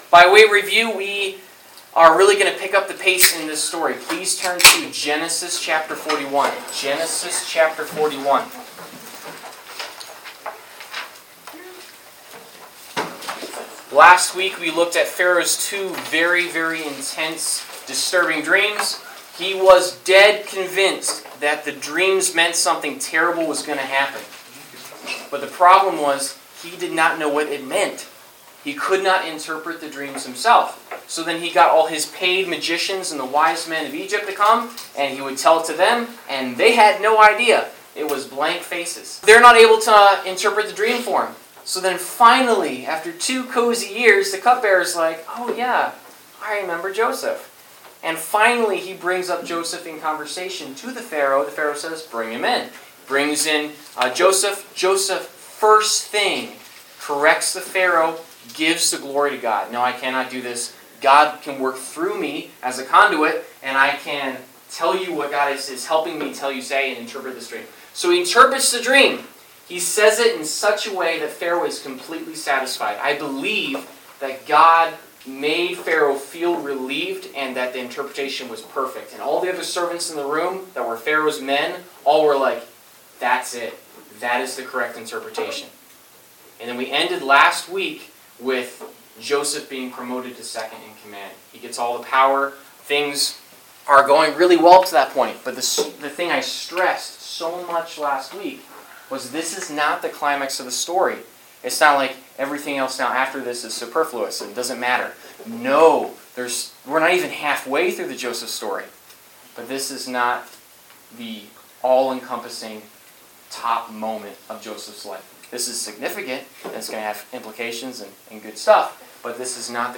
Service Type: Wednesday Night - Youth Group